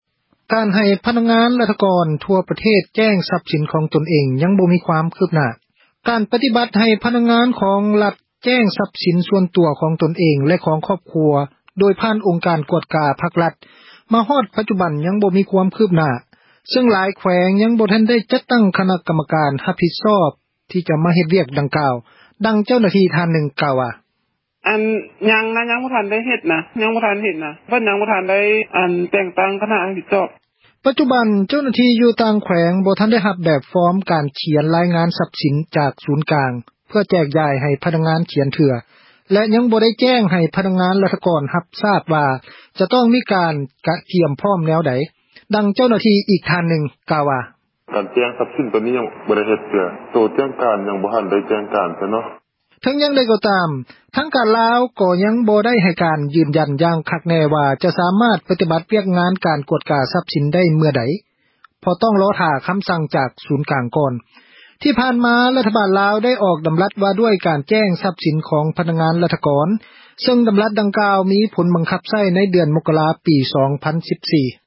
ການ ປະຕິບັດ ວຽກງານ ໃຫ້ ພະນັກງານ ຂອງຣັຖ ແຈ້ງ ຊັບສິນ ສ່ວນຕົວ ຂອງຕົນ ແລະ ຄອບຄົວ ໂດຍຜ່ານ ອົງການ ກວດກາ ພັກ-ຣັຖ ມາຮອດ ປັດຈຸບັນ ຍັງບໍ່ມີ ຄວາມຄືບໜ້າ ຊຶ່ງຫລາຍ ແຂວງ ຍັງບໍ່ທັນ ຈັດຕັ້ງ ຄະນະ ກັມມະການ ຮັບຜິດຊອບ ທີ່ ຈະມາ ດໍາເນີນ ວຽກງານ ດັ່ງກ່າວ. ດັ່ງ ເຈົ້າໜ້າທີ່ ທ່ານນຶ່ງ ກ່າວວ່າ:
ເຈົ້າໜ້າທີ່ ອີກທ່ານນຶ່ງ ກ່າວວ່າ: